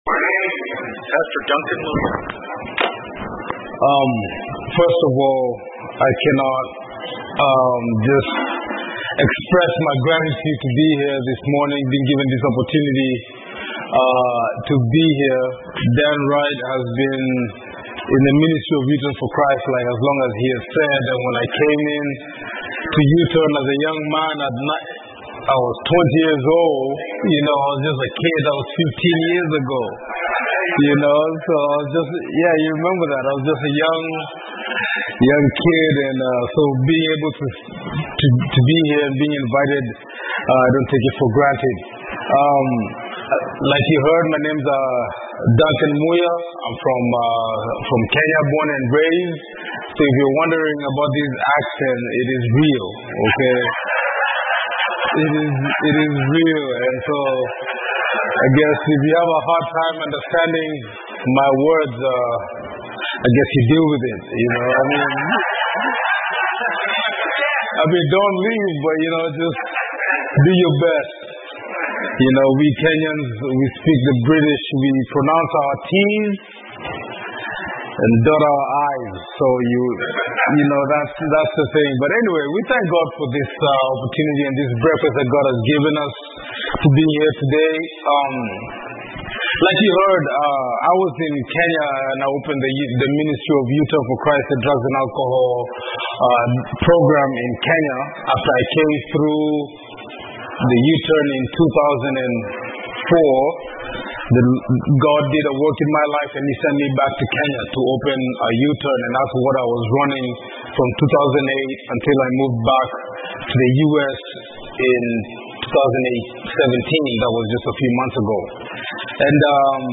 Iron Man Men's Prayer Breakfast, Main Street Pizza and Cafe Irvine